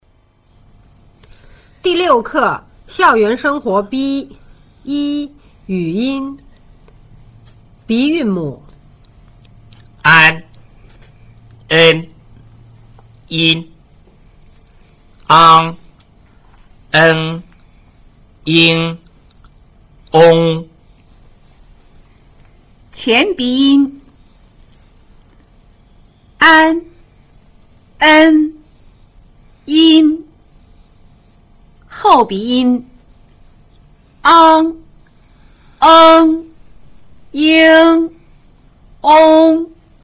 一、語音